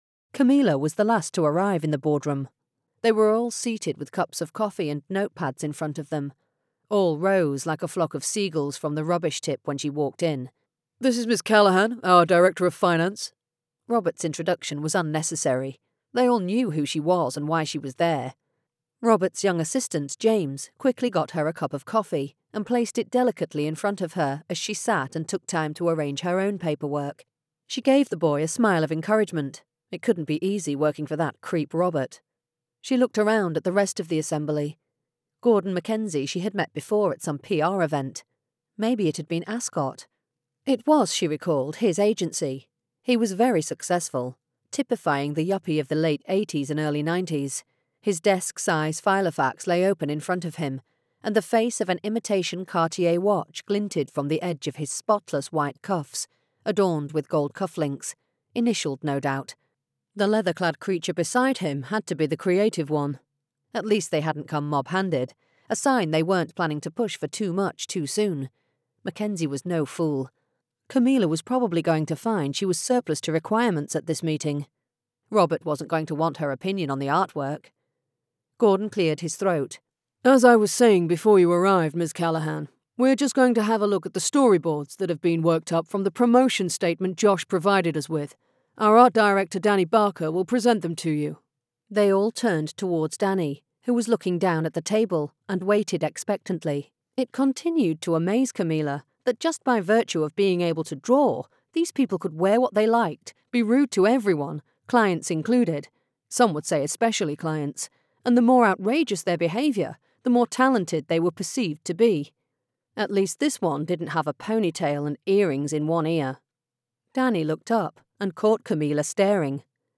Changing Perspectives by Jen Silver [Audiobook]